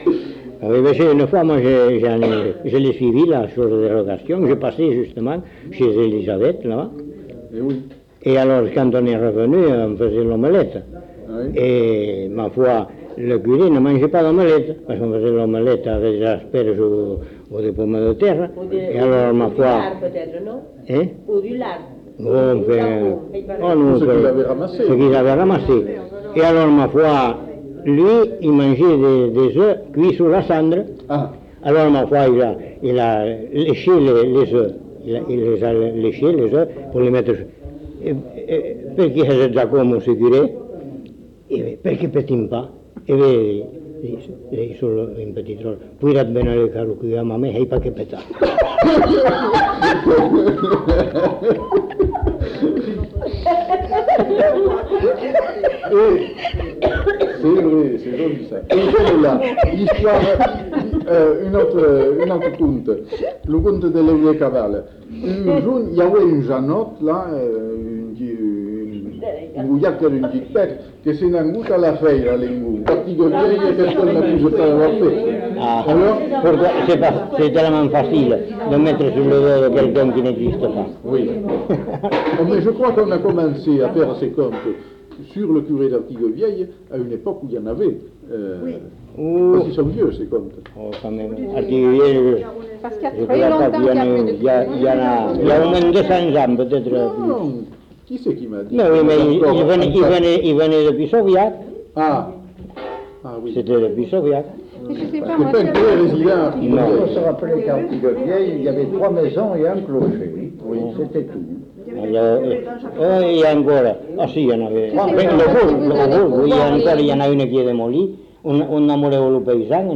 Lieu : Bazas
Genre : conte-légende-récit
Type de voix : voix d'homme
Production du son : parlé
Classification : conte facétieux